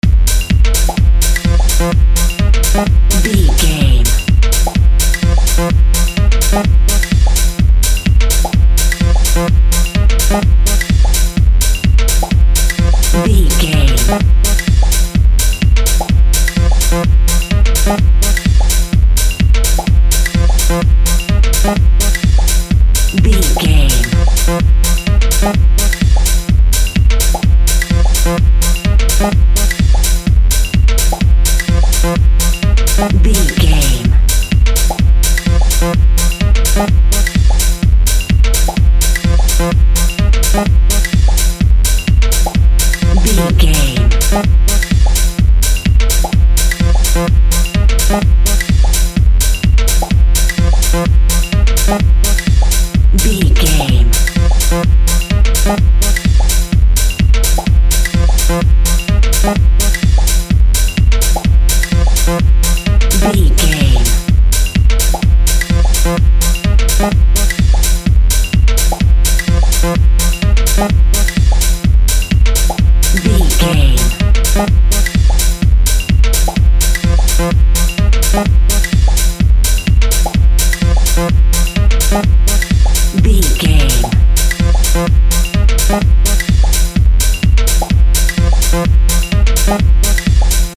Club Music Cue.
Aeolian/Minor
funky
groovy
uplifting
driving
energetic
synthesiser
drum machine
Drum and bass
break beat
electronic
sub bass